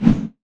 melee_swipe.wav